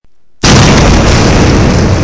explosion_atomic.wav